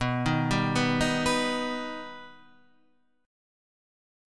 B Augmented